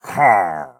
Minecraft Version Minecraft Version snapshot Latest Release | Latest Snapshot snapshot / assets / minecraft / sounds / mob / pillager / idle2.ogg Compare With Compare With Latest Release | Latest Snapshot